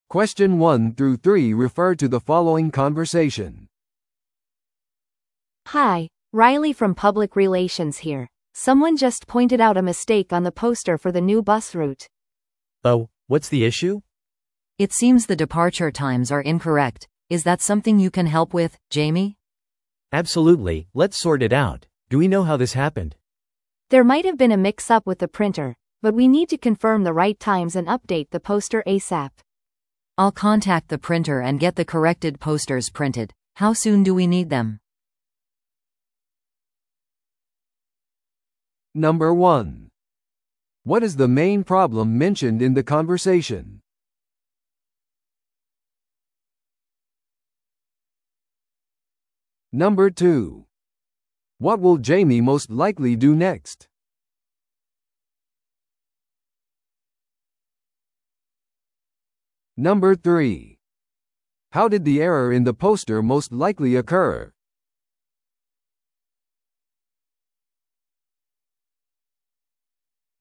No.1. What is the main problem mentioned in the conversation?